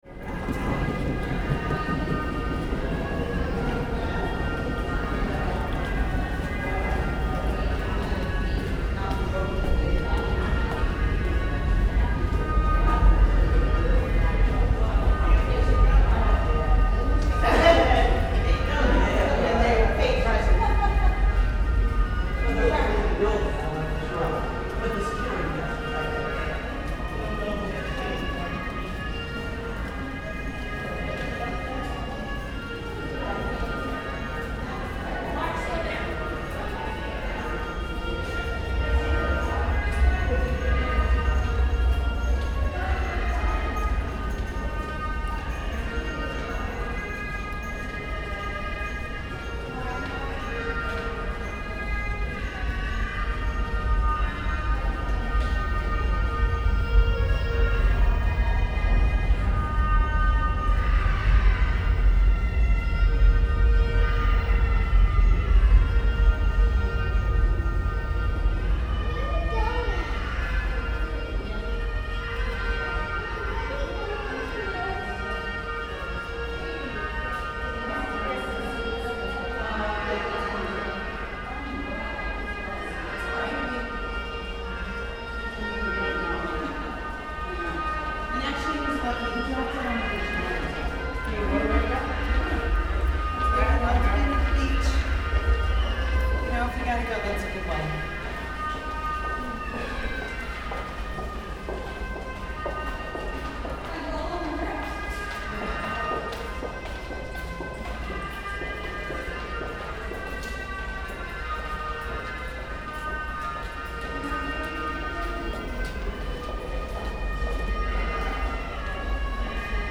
• place: en route Bedford - Manhattan, New York - Glen Ridge, New Jersey
But despite the material over-load, managed to make a few nice audio samples from deep in the city.
(00:03:49, stereo audio, 7.4 mb)